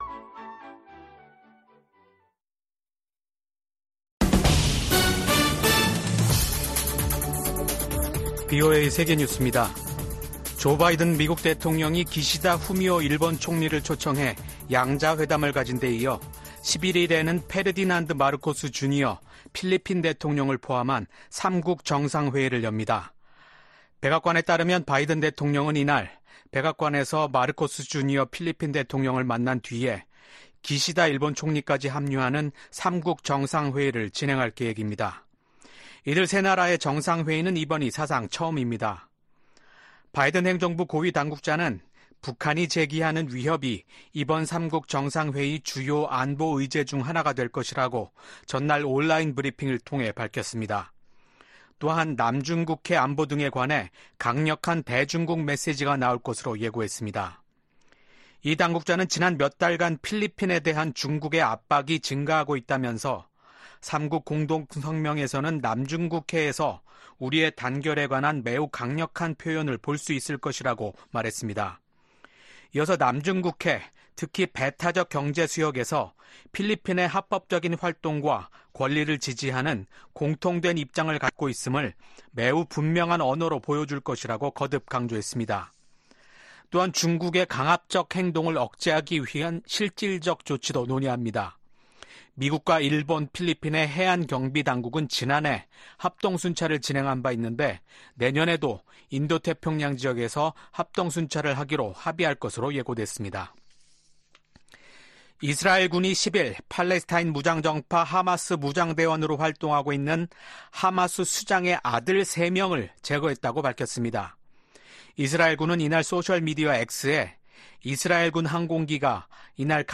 VOA 한국어 아침 뉴스 프로그램 '워싱턴 뉴스 광장' 2024년 4월 12일 방송입니다. 조 바이든 미국 대통령과 기시다 후미오 일본 총리가 정상회담을 열고 북한 문제와 군사 구조 재편 방안 등을 논의했습니다. 미국 상원과 하원에서 일본 총리의 미국 방문을 환영하는 결의안이 발의됐습니다. 어제 치러진 한국의 국회의원 총선거가 야당의 압승으로 끝난 가운데 탈북민 출신 4호 의원이 당선됐습니다.